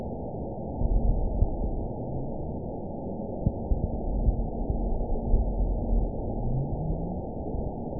event 915930 date 12/20/22 time 09:41:47 GMT (2 years, 4 months ago) score 8.86 location INACTIVE detected by nrw target species NRW annotations +NRW Spectrogram: Frequency (kHz) vs. Time (s) audio not available .wav